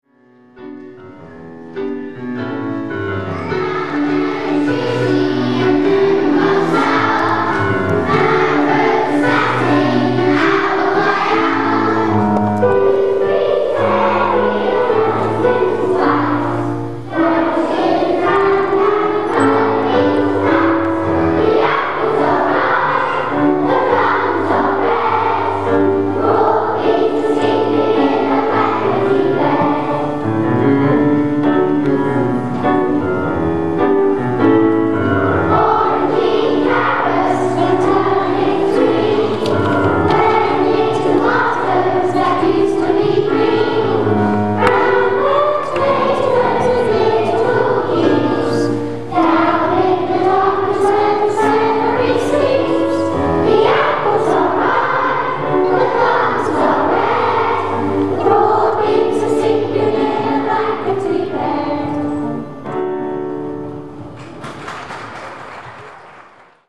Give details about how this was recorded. Harvest Festival at Loders Church